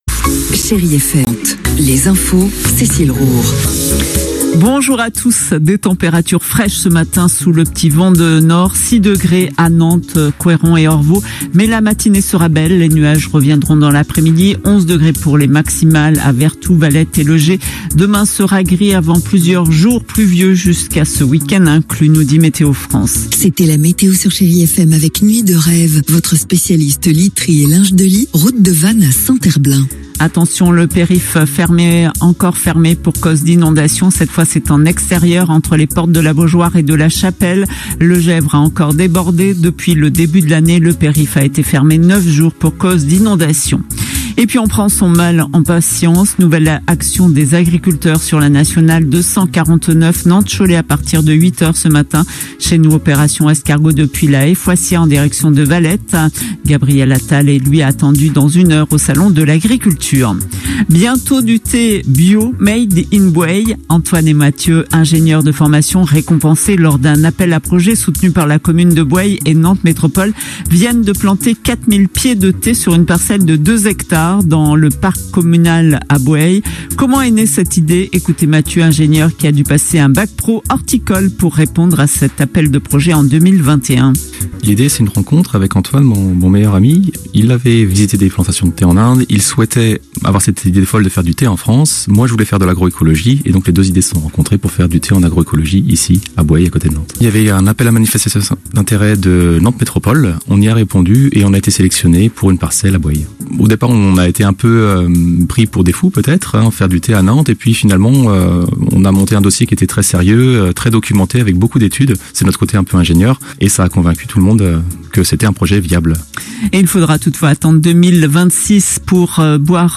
Flashs infos : 4 présentations différentes du Jardin de Thé